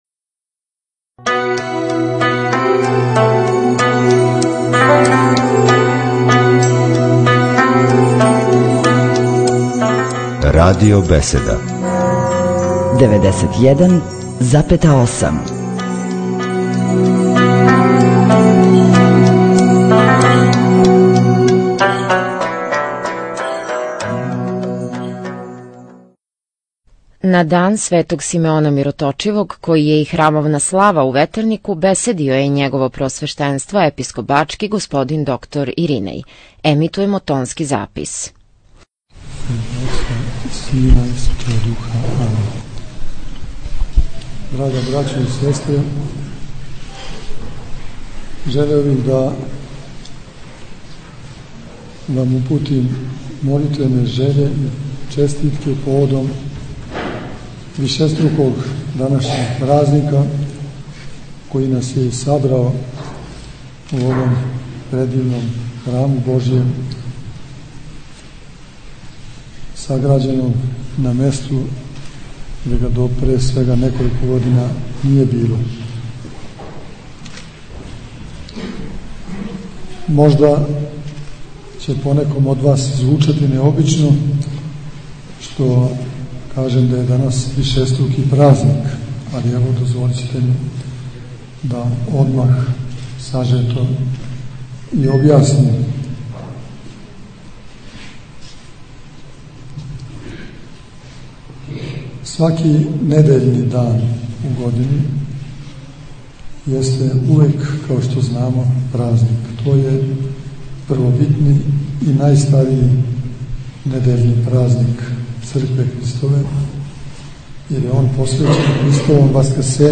У Недељу Месопусну, 27. фебруара 2011. године, у Ветернику је прослављена храмовна слава, празник Преподобног Симеона Мироточивог. Литургијско славље предводио је Владика Иринеј уз саслужење осам свештеника и четири ђакона.